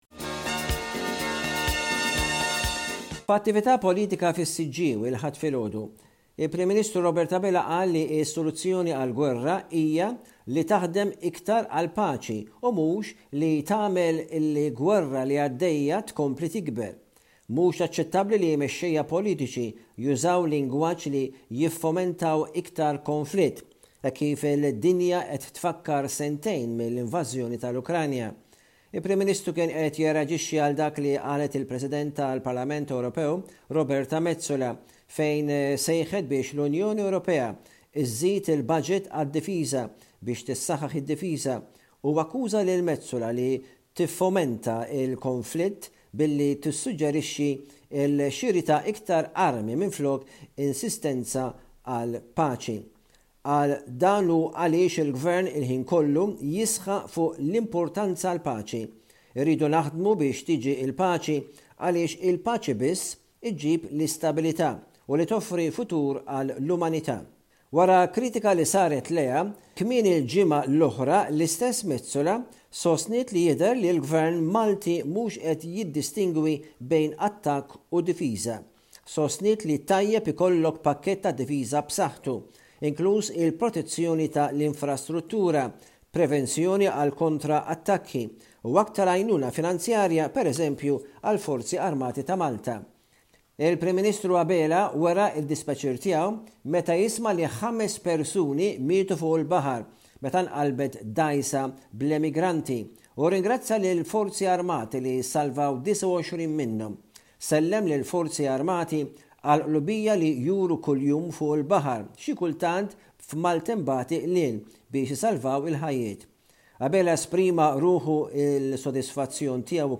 News report from Malta by SBS Radio correspondent